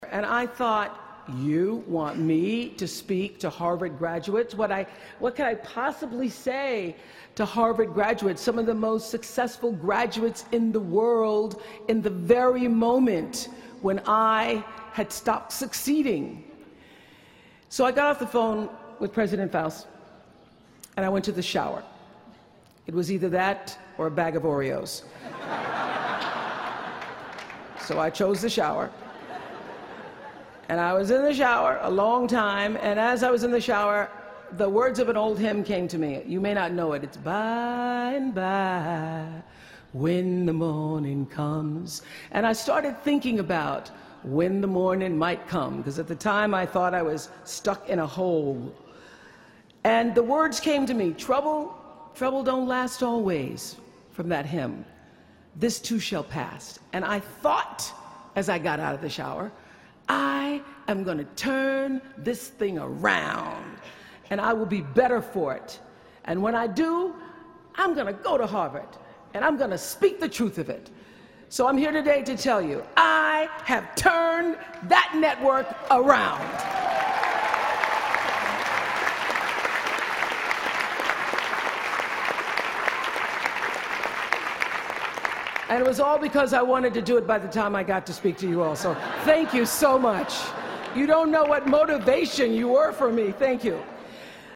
公众人物毕业演讲第356期:奥普拉2013在哈佛大学(5) 听力文件下载—在线英语听力室